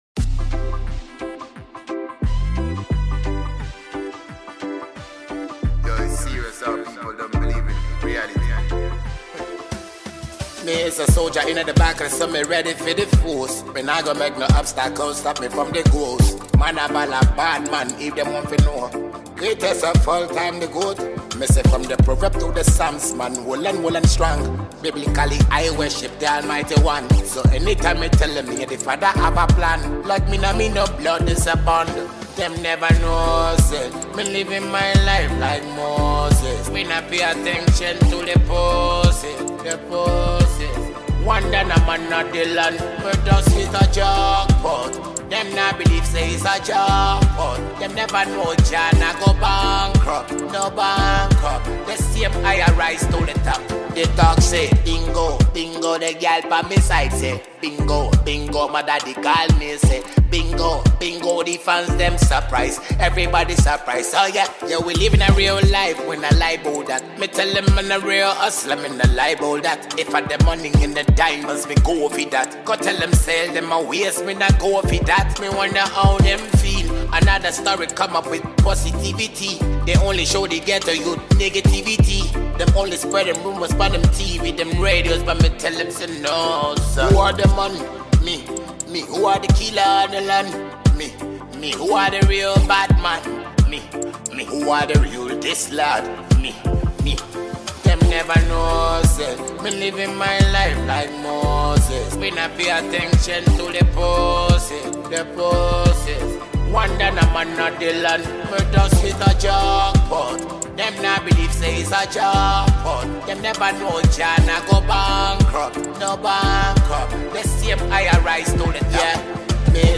Ghanaian dancehall musician